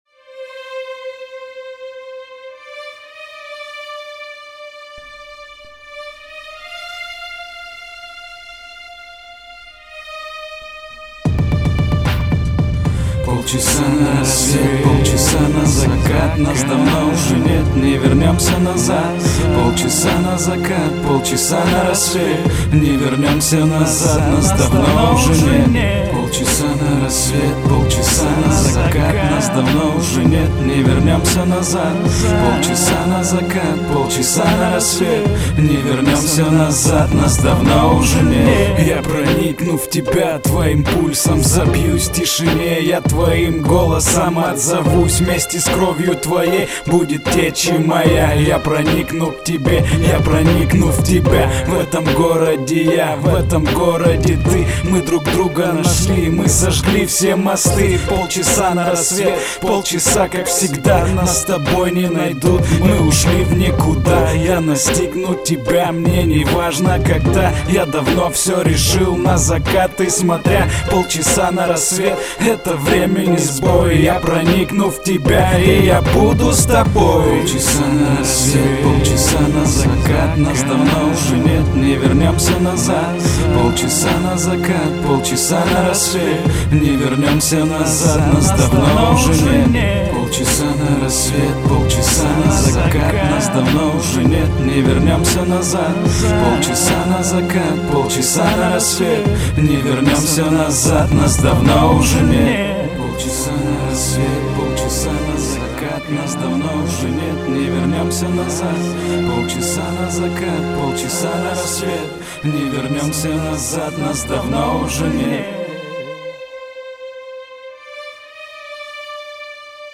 Музыка - тоска, тоскую...